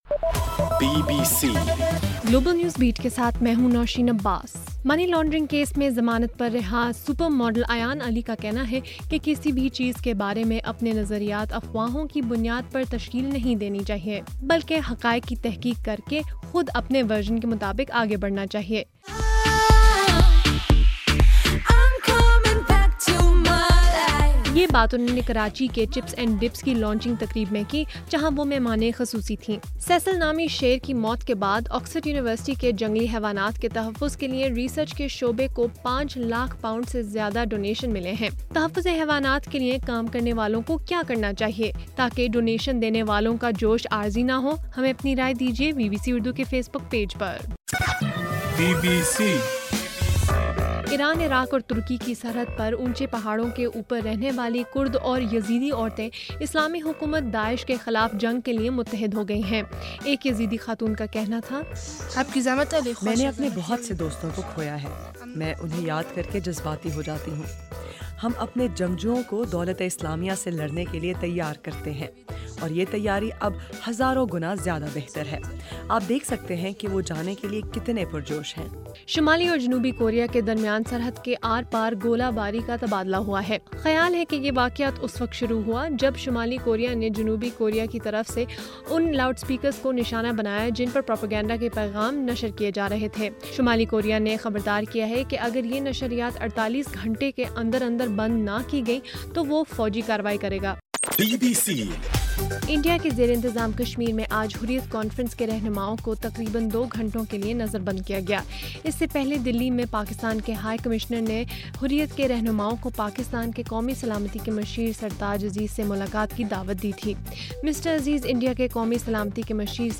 اگست 20: رات 9 بجے کا گلوبل نیوز بیٹ بُلیٹن